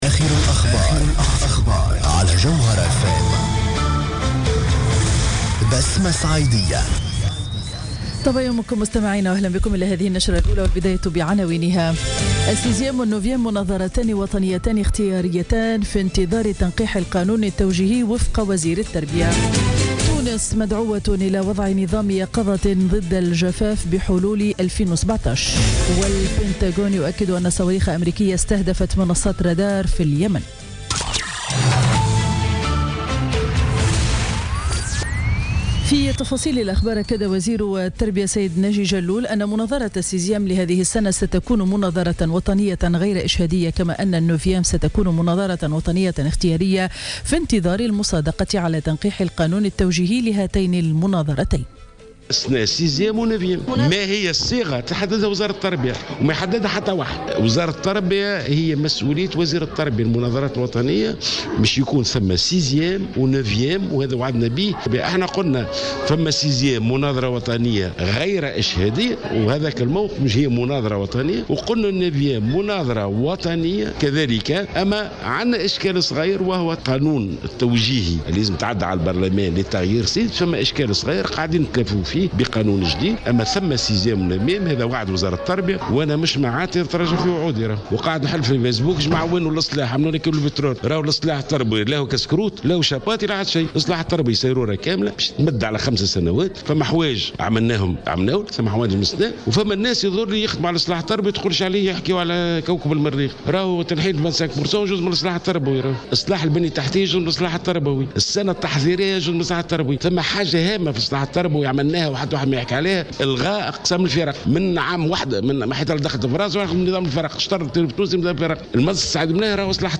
نشرة أخبار السابعة صباحا ليوم الخميس 13 أكتوبر 2016